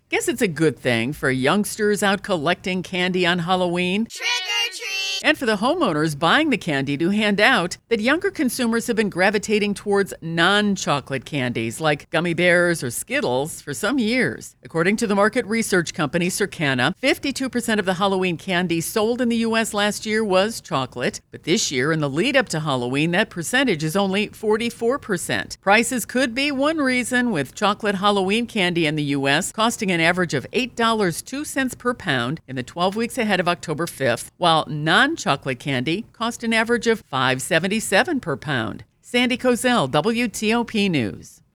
reports that chocolate candy could take a back seat to non-chocolate treats this Halloween.